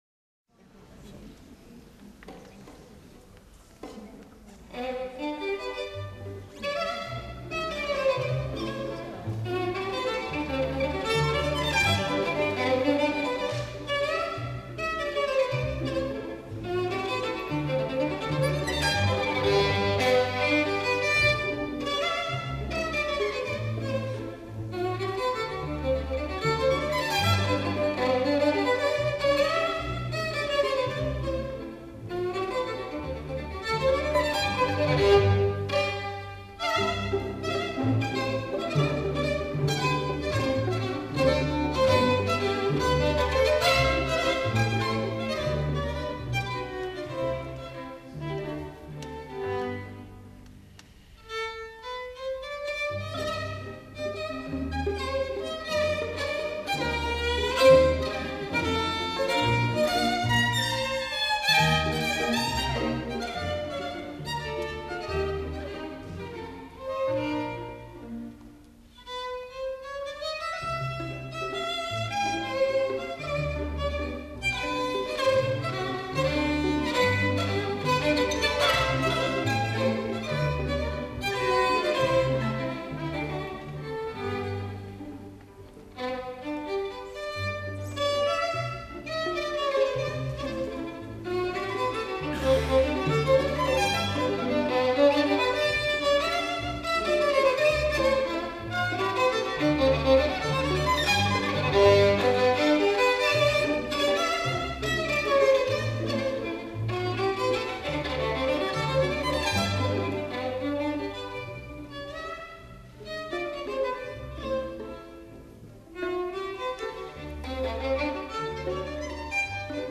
Leidenschaft pur
Konzertmitschnitt vom 15. Mai 2008, Baden-Baden.